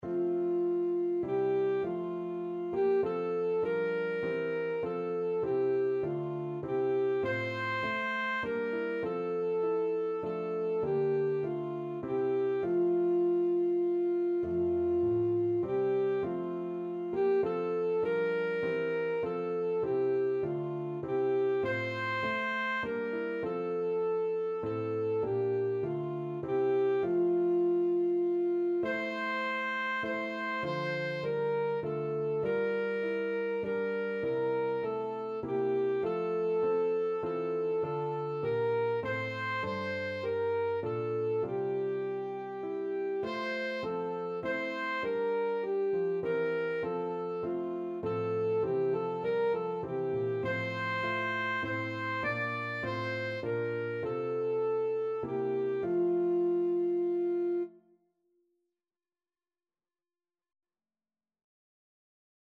Christian
Alto Saxophone
3/4 (View more 3/4 Music)
Classical (View more Classical Saxophone Music)